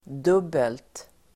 Uttal: [d'ub:elt]